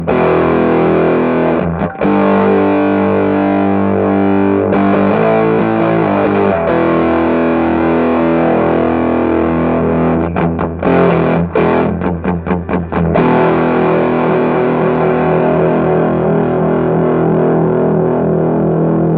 Guitar Fuzz Effects Pedal Kit - Silicon NPN
This kit is based on the legendary fuzz face pedal. Instead of germanium transistors, it uses newer, more commonly available NPN silicon transistors to give you a harsh and sharp fuzz, while keeping the cost down.